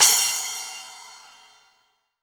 2SA CYMB.wav